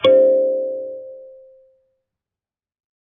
kalimba2_wood-C4-pp.wav